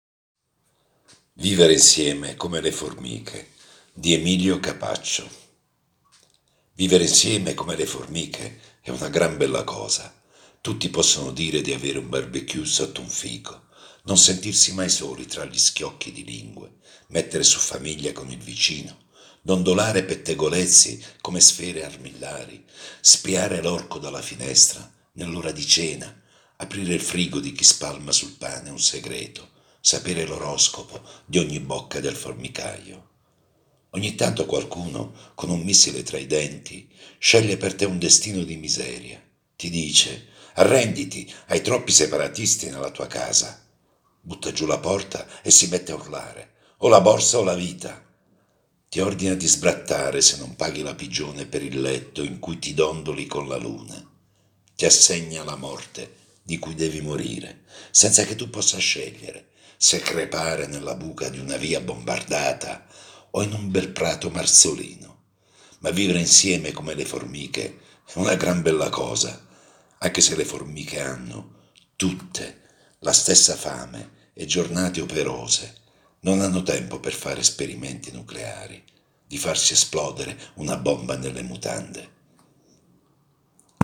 Solo un’avvertenza: la voce narrante è quella di un lettore comune e non l’espressione professionale di un attore, così come l’ambiente operativo che non è uno studio di registrazione.